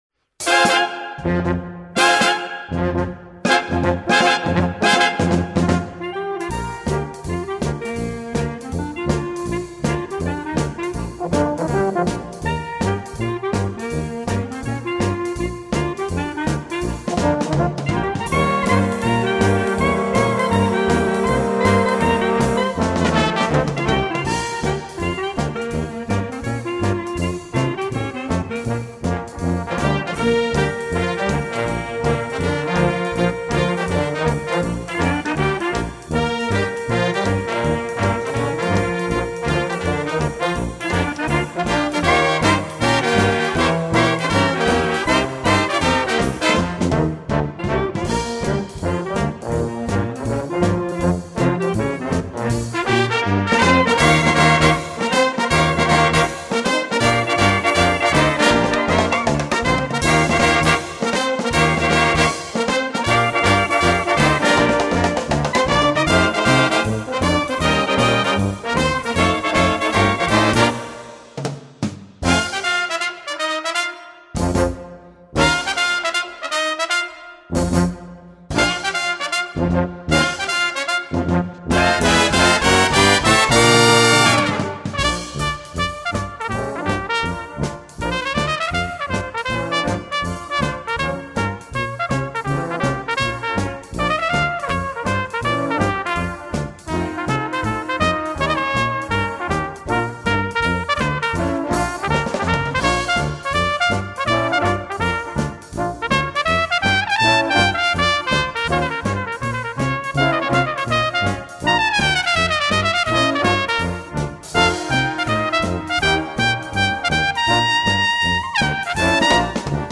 Swingy